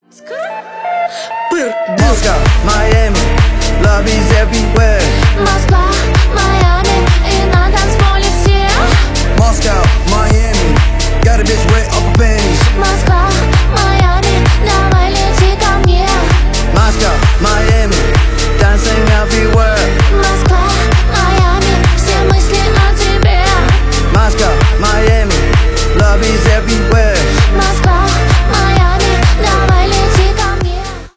дуэт , рэп